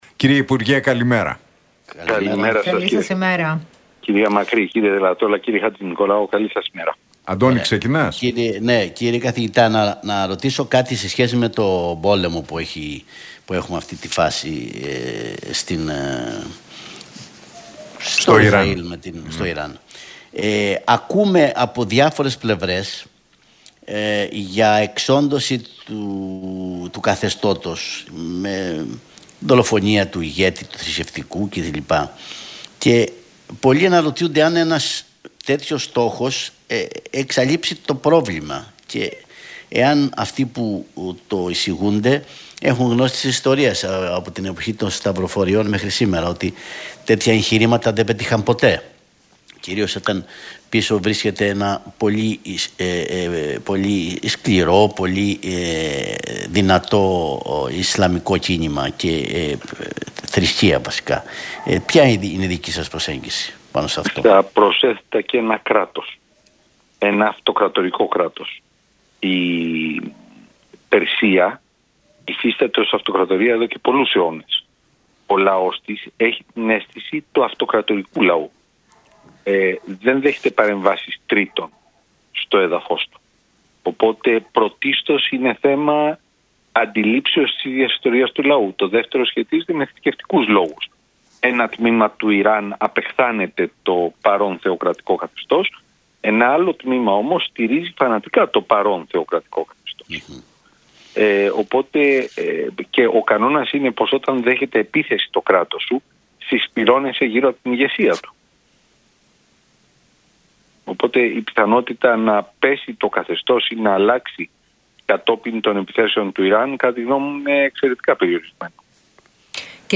Συρίγος στον Realfm 97,8 για τον Θαλάσσιο Χωροταξικό Σχεδιασμό της Τουρκίας: Δεν προσδιορίζει σε ποιον ανήκουν τα νησιά, ούτε ποια θεωρεί ότι είναι «γκρίζες ζώνες»
από την συχνότητα του Realfm 97,8.